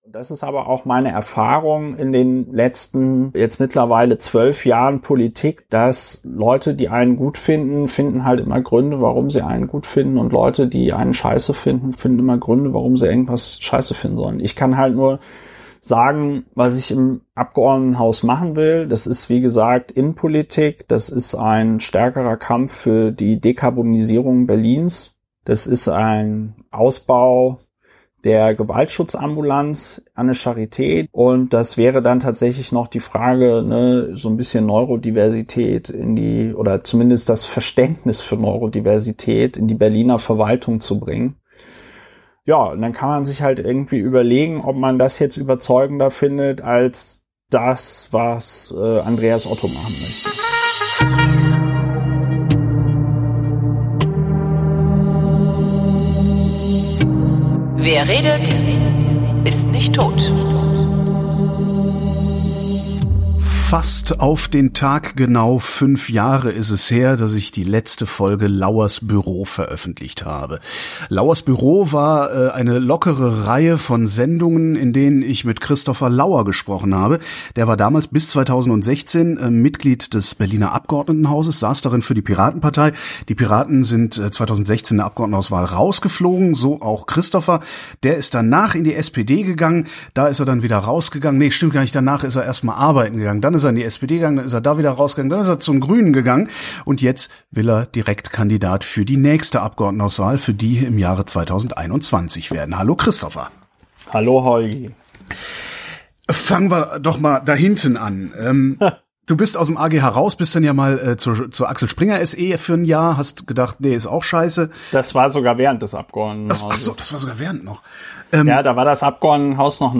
Christopher Lauer hat angekündigt, zur Berliner Abgeordnetenhauswahl im Septemper 2021 als Direktkandidat für die Grünen antreten zu wollen. Am 6. März 2021 findet die Aufstellungsversammlung statt, bei der die Partei ihre Direktkandidaten festlegt – und weil Christopher bis vor fünf Jahren regelmäßiger Gast in meinen Sendungen war, habe ich diese Gelegenheit genutzt, mich mal wieder mit ihm zusammenzusetzen und zu plaudern.